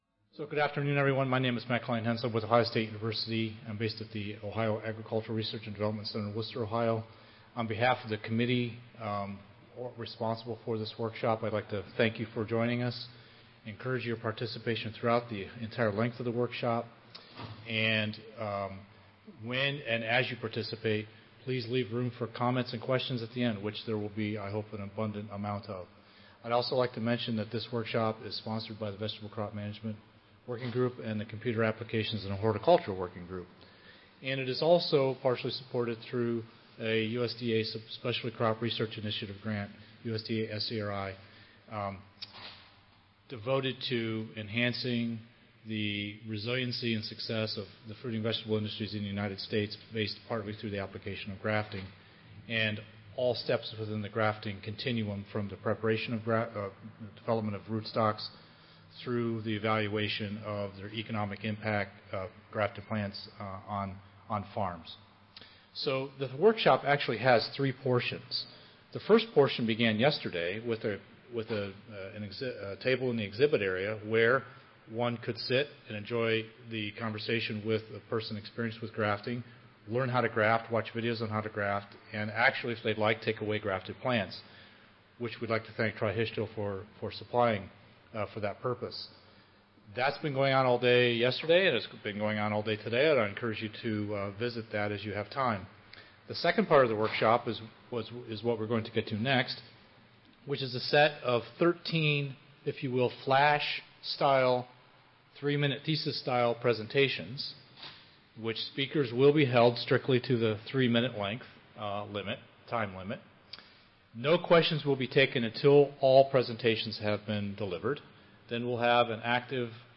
2018 ASHS Annual Conference
Audio File Recorded Presentation